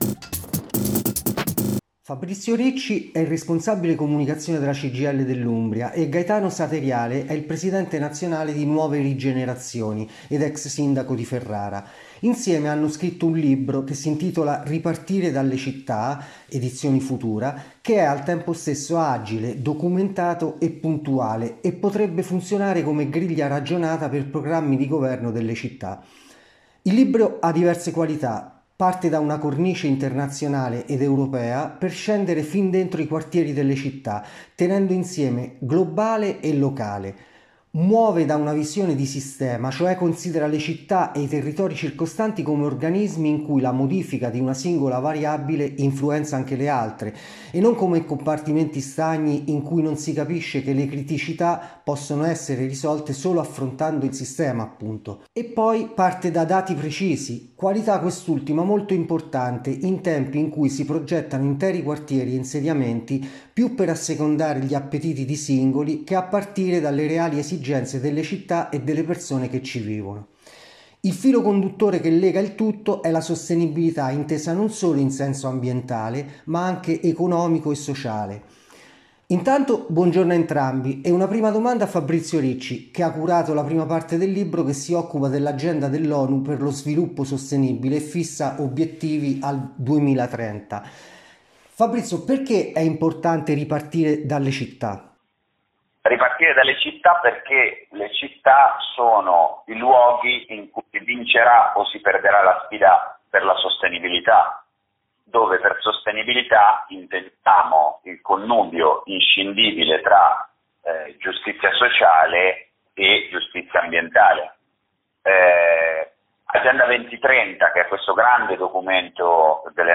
Li abbiamo intervistati.